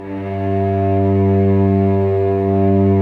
Index of /90_sSampleCDs/Roland LCDP13 String Sections/STR_Vcs II/STR_Vcs6 p Amb